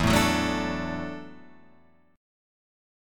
E7sus2sus4 chord